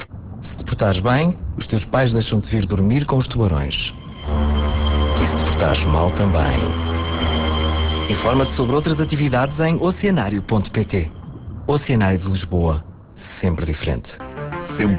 Estreou dia 30 de Junho uma campanha do Oceanário de Lisboa. Esta campanha conta com 3 spots que passam simultaneamente na RR, RFM e RCP.